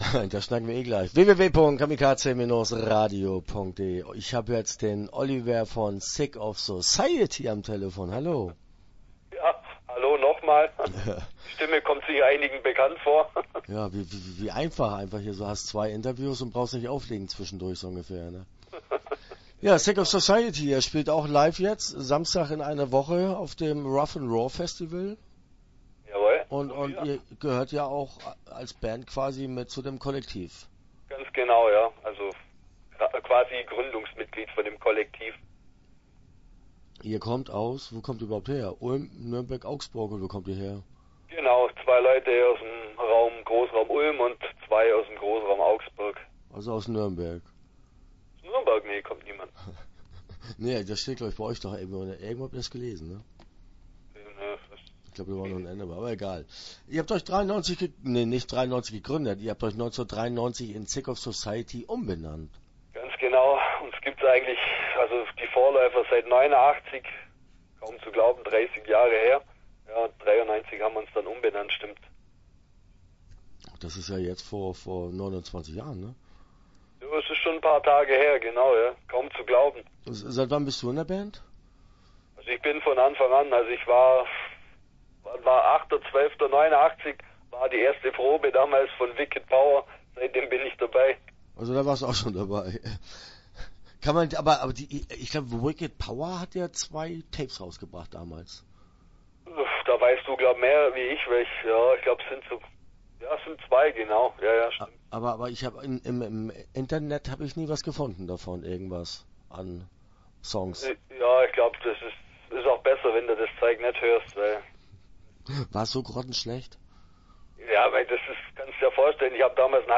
Sick Of Society - Interview Teil 1 (11:00)